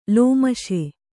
♪ lōmaśe